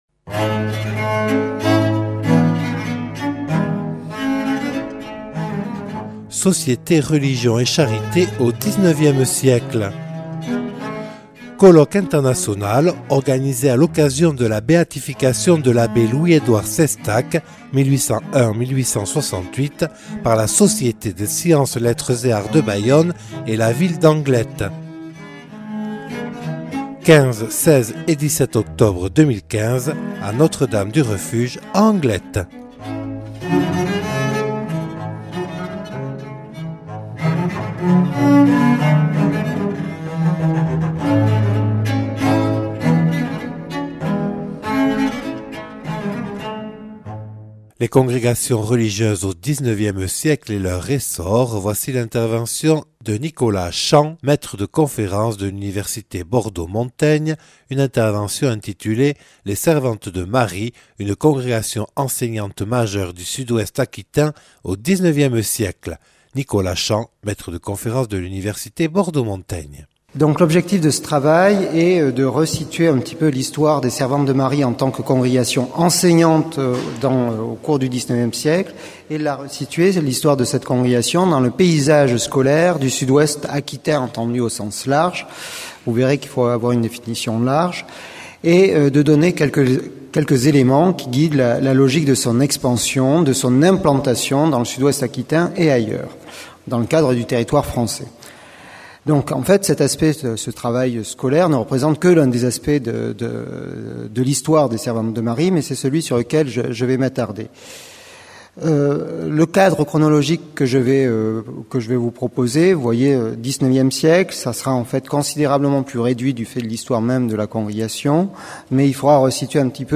(Enregistré le 15/10/2015 à Notre Dame du Refuge à Anglet).